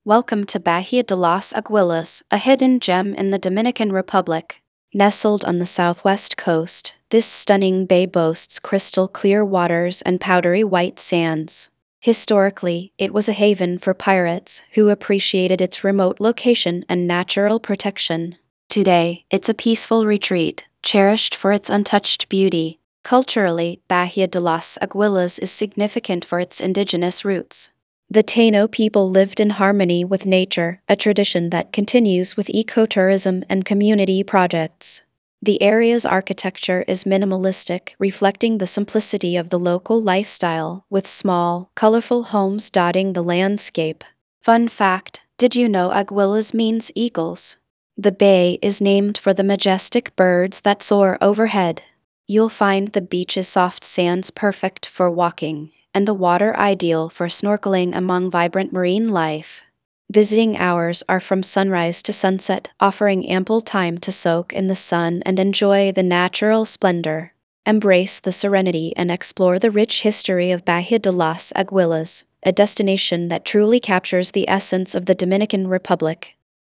tts / cache